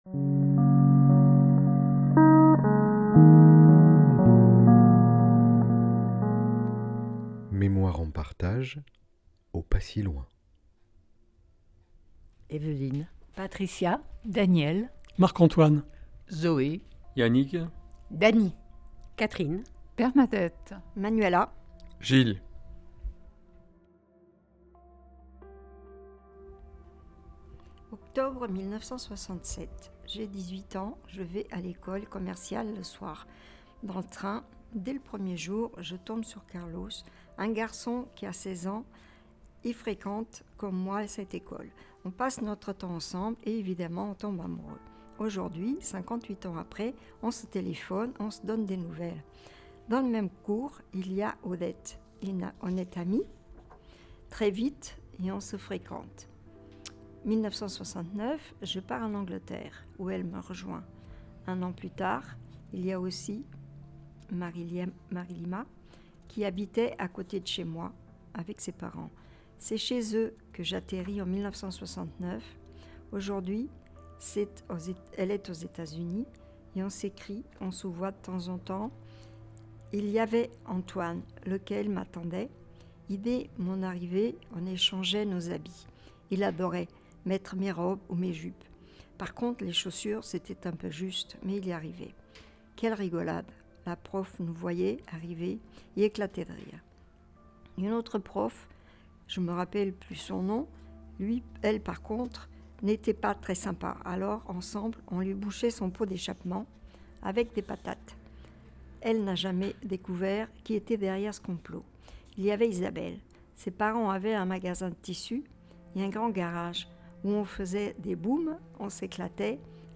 Ateliers seniors et aidants – financés par la Conférence des financeurs de Seine-Saint-Denis